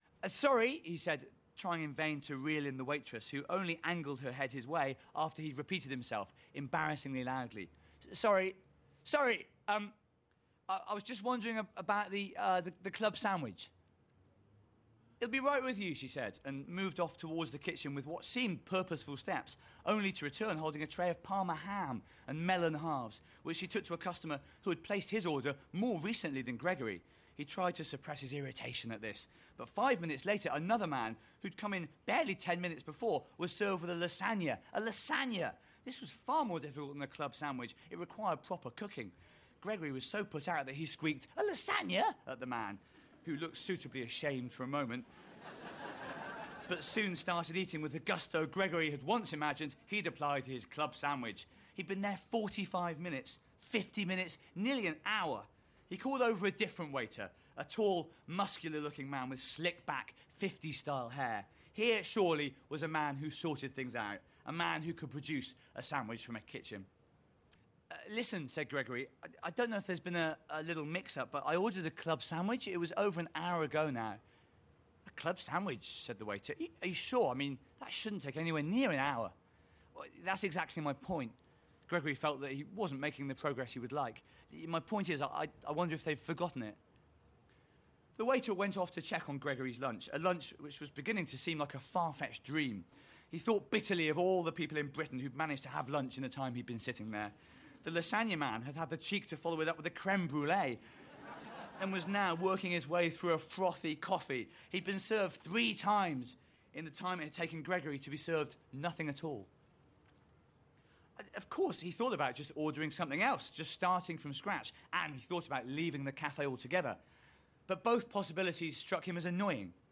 Recorded live at this year’s Edinburgh Fringe, Mark Watson tells the comic story of one man’s epic quest for lunch. Philosophy professor Dr Gregory Samson has a rare afternoon off. Starving hungry, he enters a café where he fantasises about the chunky club sandwich that he has just ordered.